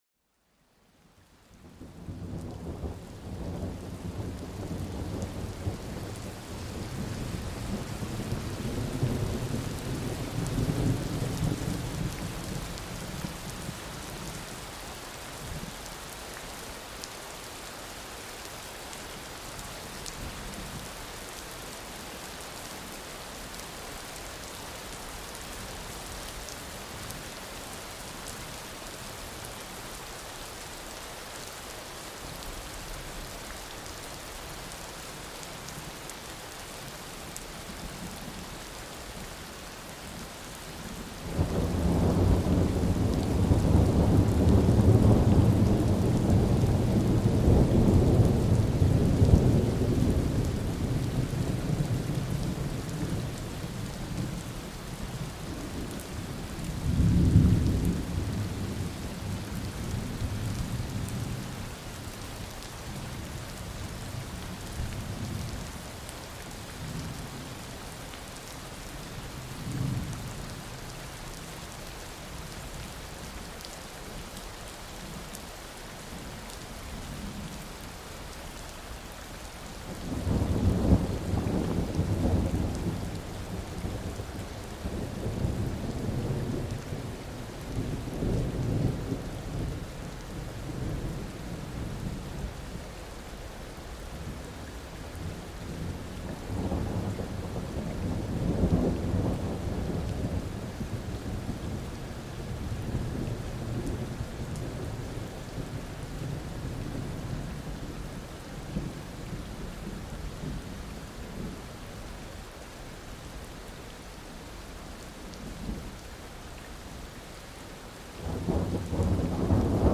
⚡ Plongez dans le calme d'un orage puissant pour vous endormir paisiblement
Le grondement de l'orage crée une ambiance apaisante qui aide à calmer l'esprit. Les éclairs et la pluie apportent un sentiment de tranquillité malgré la tempête.